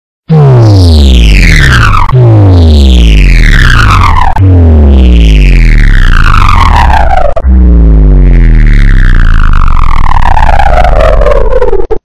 PoweringOffSound.ogg